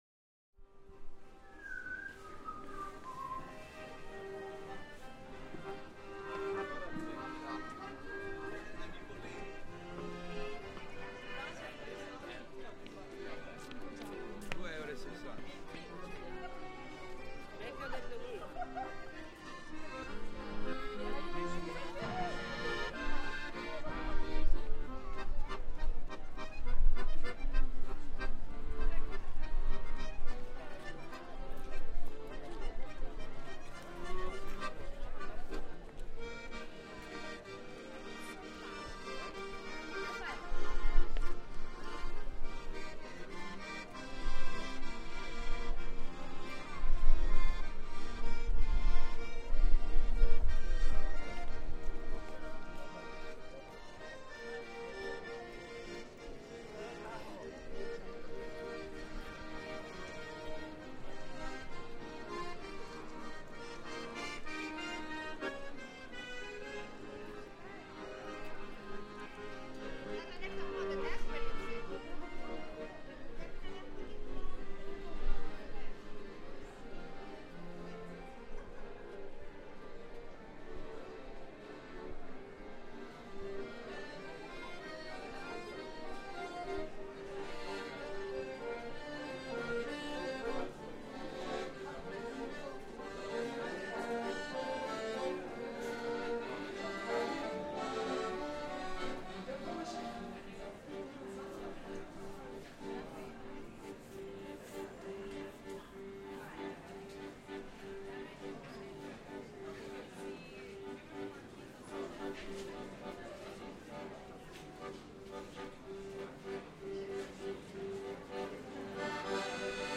A collection of accordions
The Halászbástya or Fisherman's Bastion is one of the most recognisable monuments in Budapest, and a major tourist attraction, not just for the beauty of the space itself, but for the views of the city it affords.
As such, it's buzzing with people, and where there are tourists, there are local musicians, street vendors and so on. This recording captures multiple accordion players playing traditional tunes among the crowds of tourists.